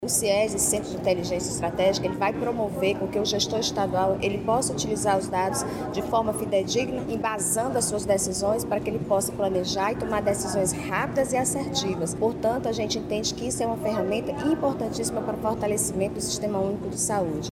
A presidente do Conselho Nacional de Secretários de Saúde – Conass, Tânia Mara Coelho, ressalta que a estrutura vai proporcionar melhor embasamento aos gestores, nos momentos de tomadas de decisão.